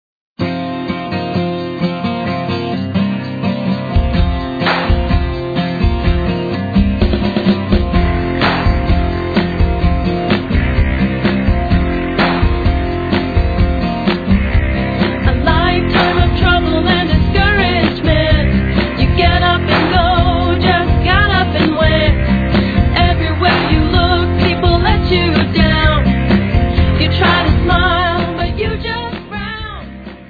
Christian lyrics written to the tune of popular songs
You will love the upbeat music and fun Christian message.